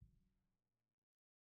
Percussion / Timpani
Timpani5_Hit_v1_rr2_Sum.wav